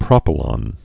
(prŏpə-lŏn, prōpə-)